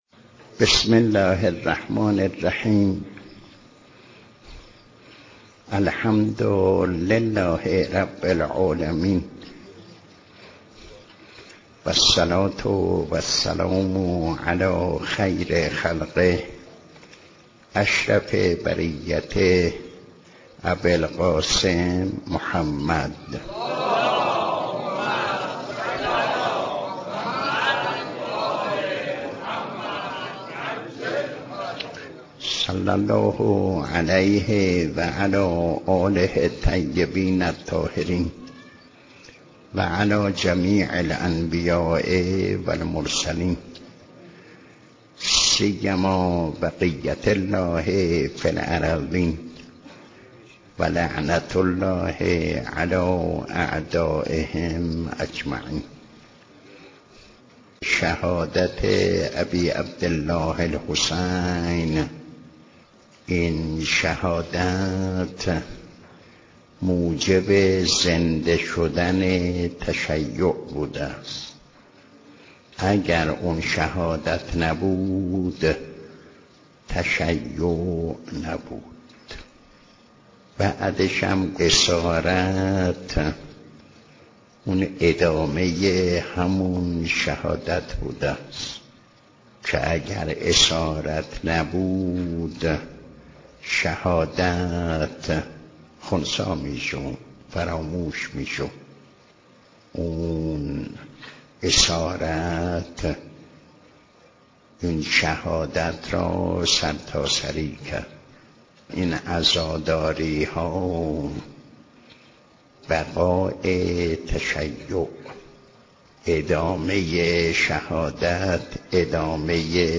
درس اخلاق | کربلا آغاز زندگی بود، زینب (س) آن را جهانی کرد